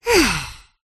Звуки тяжелого вздоха
звук облегченного вздоха